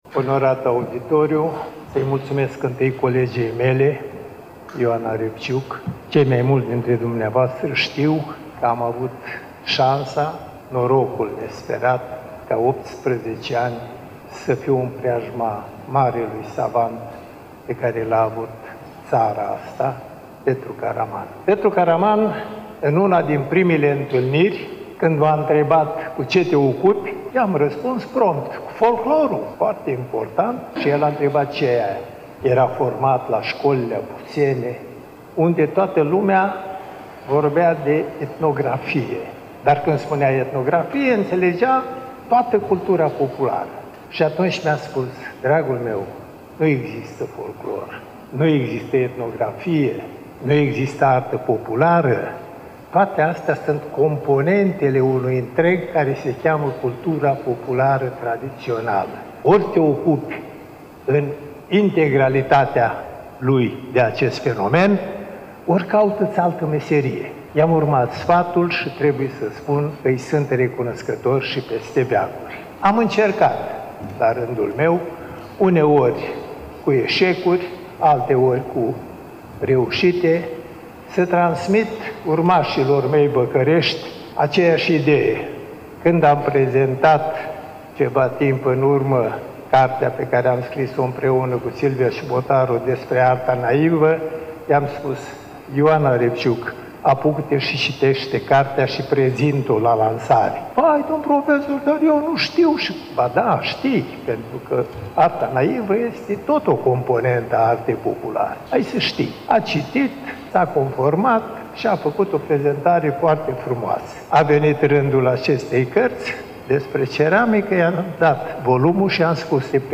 Volumul a fost lansat, la Iași, nu demult, în Sala „Petru Caraman” din incinta Muzeului Etnografic al Moldovei, Palatul Culturii.